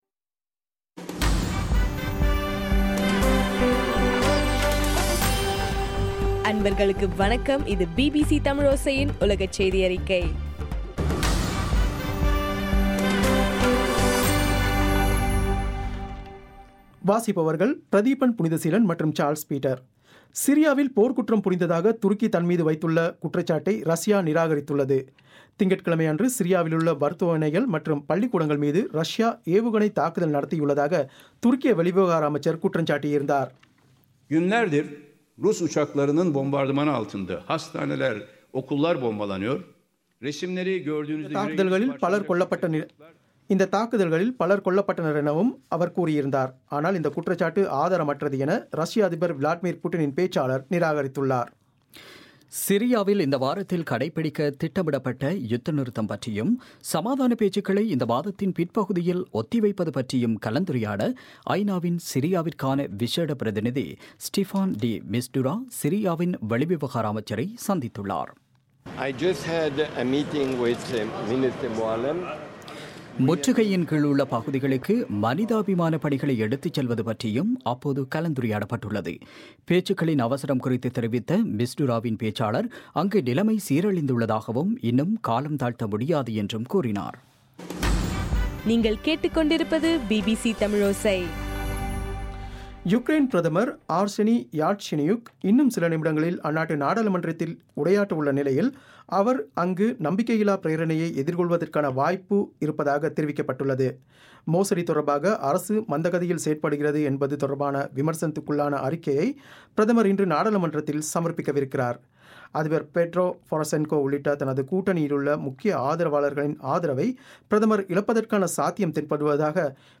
பிப்ரவரி 16 பிபிசியின் உலகச் செய்திகள்